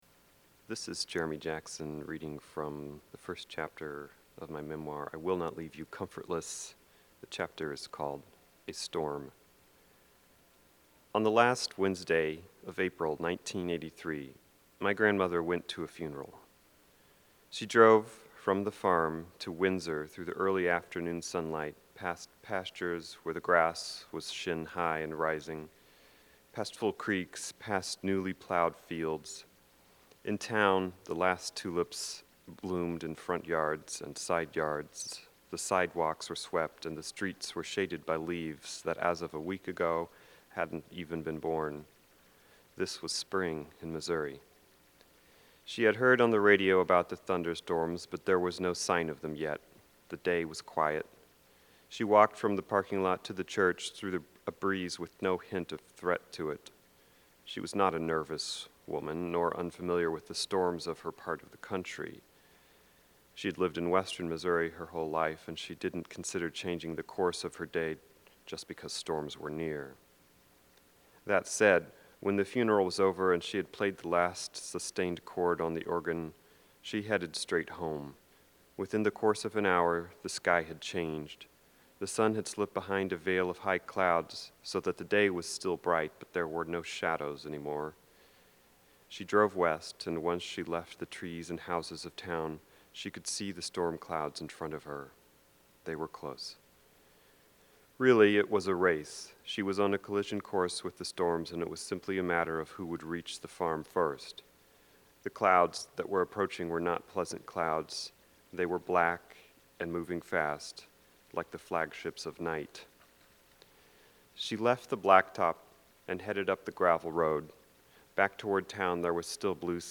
reads from his memoir